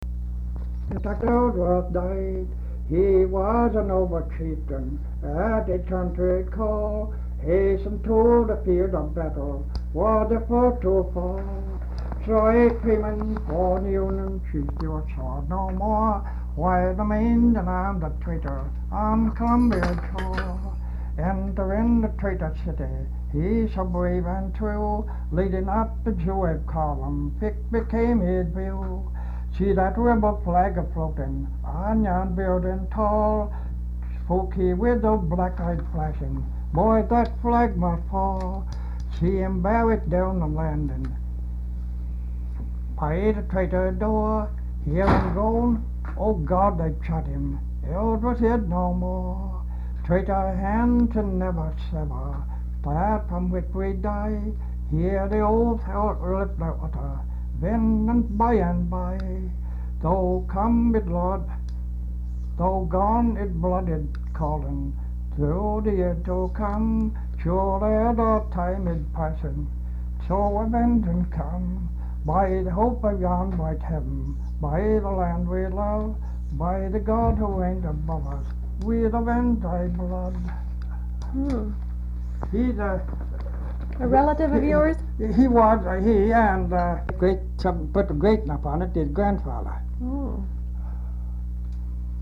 Folk songs, English--Vermont
sound tape reel (analog)